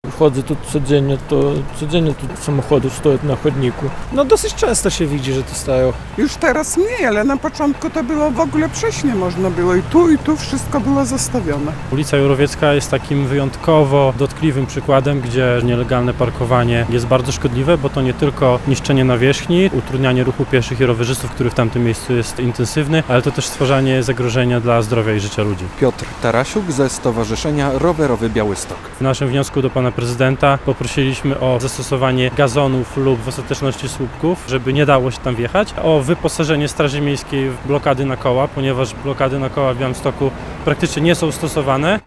Stowarzyszenie Rowerowy Białystok apeluje o walkę z nielegalnym parkowaniem przy ul. Jurowieckiej - relacja